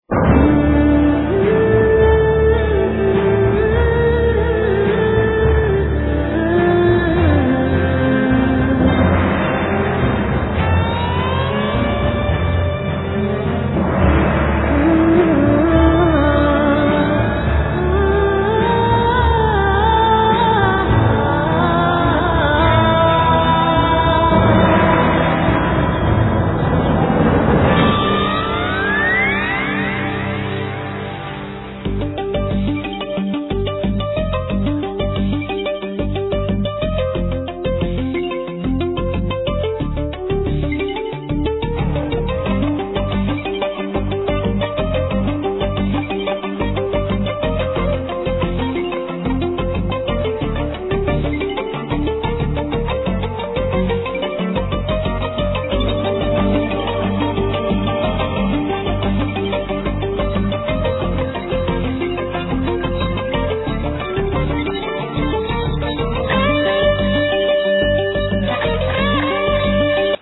Vocals, Pipes
Flute, Pipe, 7-hole flute, Tambourine, Vocals
Acoustic guitar
Tarogato (Oboe-like shawm)
Doromb (mouth harp)